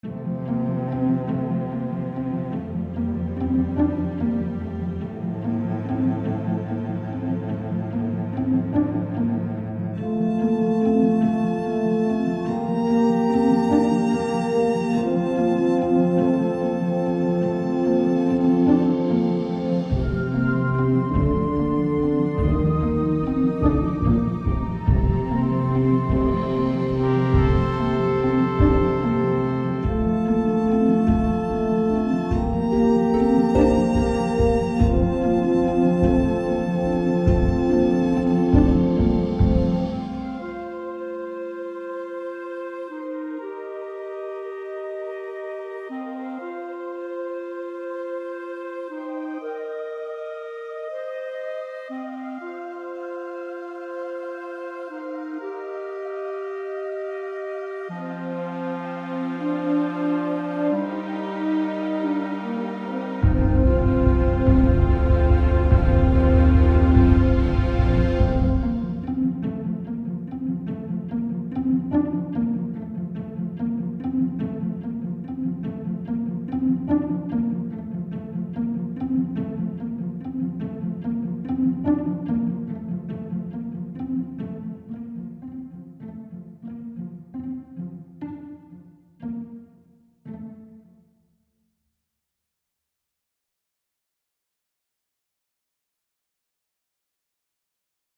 Medieval and Fantasy